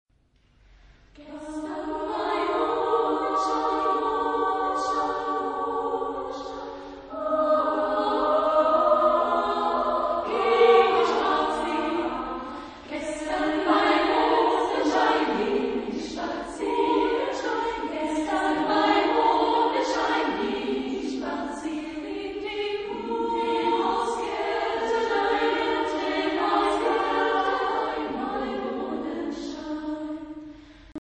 Type de choeur : SSA  (3 voix égales de femmes )